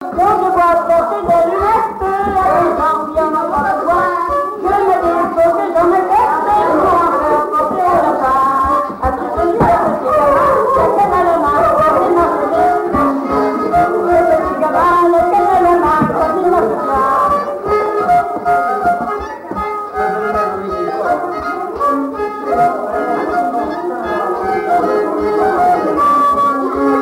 Chants brefs - A danser Résumé : Quand je vois porter des lunettes à des gens qui n'en ont pas besoin.
danse : mazurka
Musique, bal, émission de radio RCF 85
Pièce musicale inédite